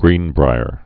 (grēnbrīər)